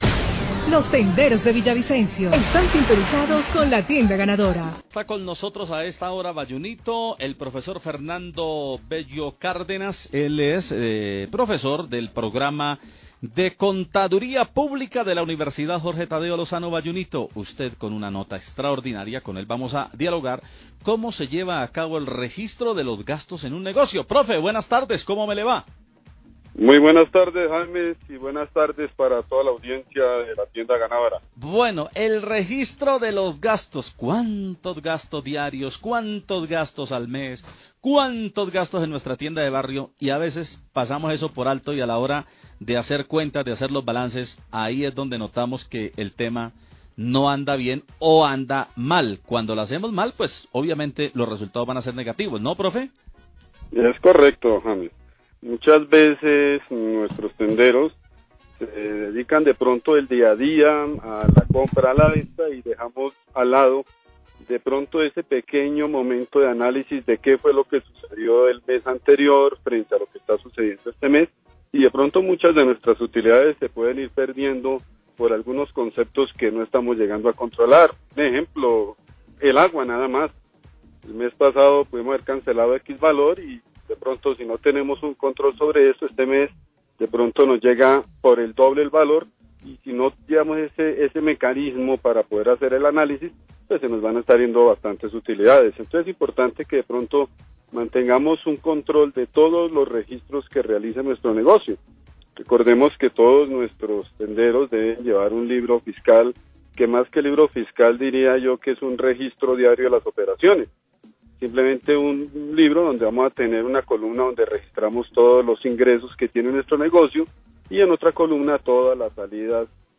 brindó algunos consejos sobre cómo ejercer el monitoreo de ingresos y gastos, en el programa La Tienda Ganadora de La Cariñosa de RCN radio.